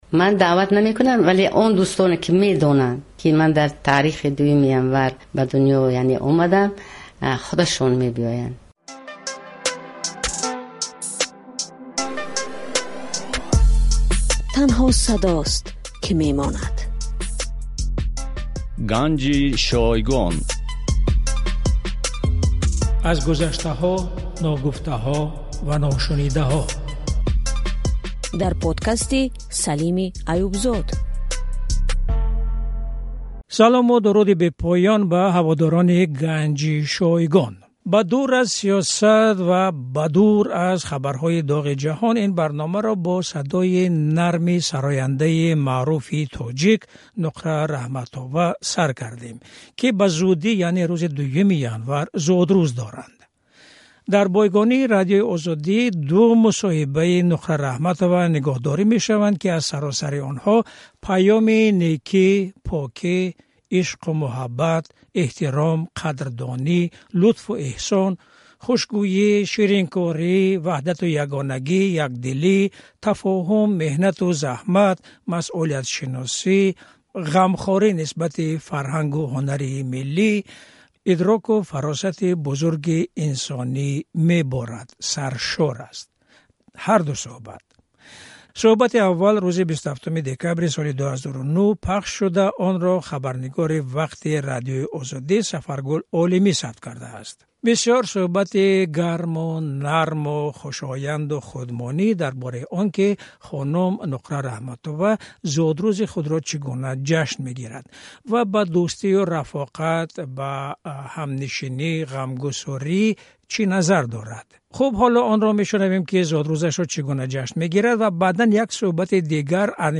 Сарояндаи машҳури тоҷик Нуқра Раҳматова ду дафъа ба Радиои Озодӣ мусоҳиба додааст. Дар онҳо чанд нуктаи пурарзиши таърихӣ, ҳунарӣ ва инсонӣ ҷой доранд.